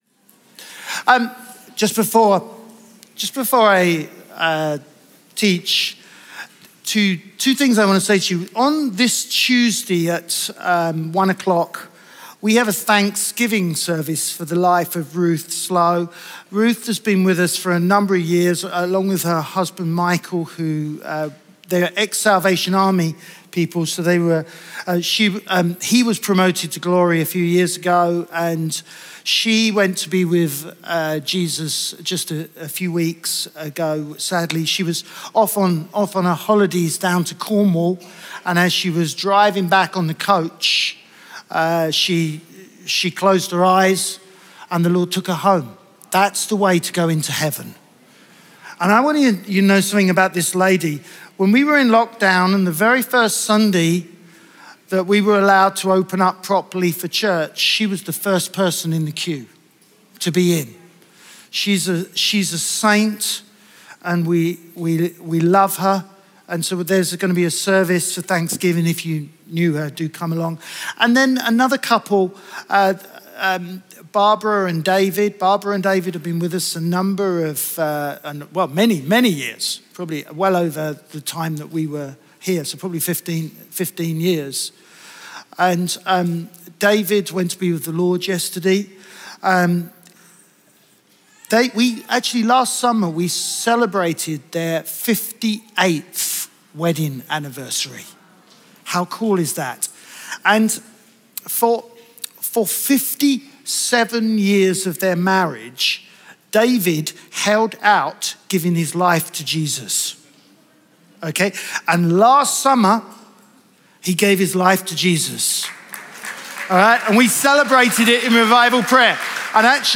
Chroma Church - Sunday Sermon Barnabas - Encountering generosity Jul 04 2023 | 00:30:53 1x 00:00 / 00:30:53 Subscribe Share RSS Feed Share Link Embed